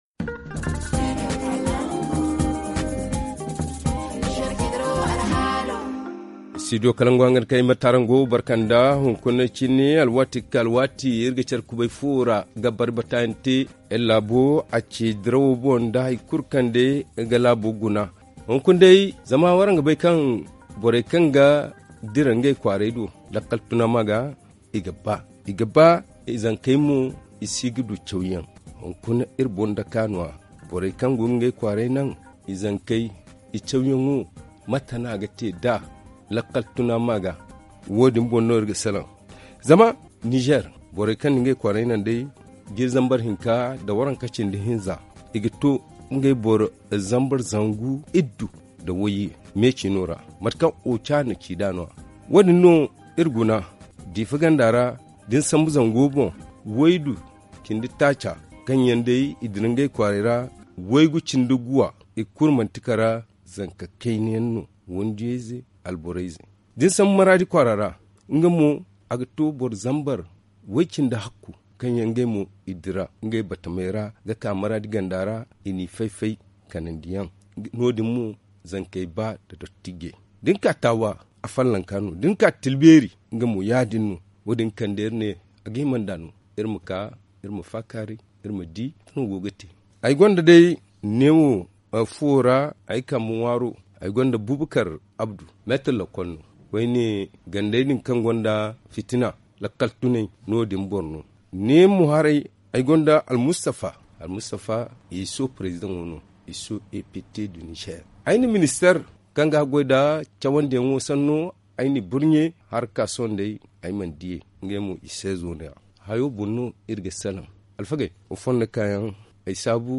Le forum en zarma